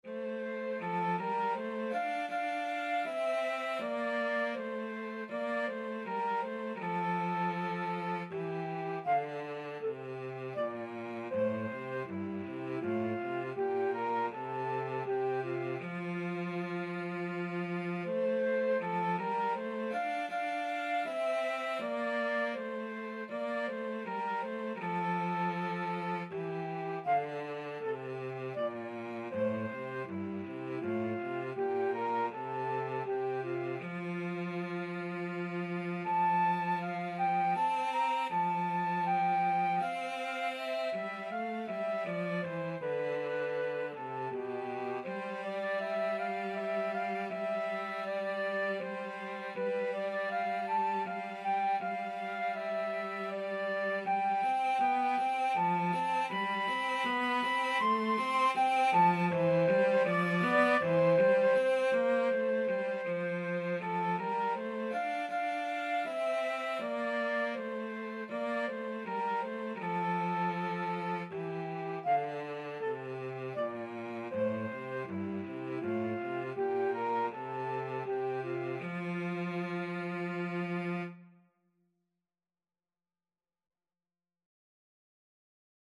Free Sheet music for Flute-Cello Duet
Andante
F major (Sounding Pitch) (View more F major Music for Flute-Cello Duet )
3/4 (View more 3/4 Music)
Classical (View more Classical Flute-Cello Duet Music)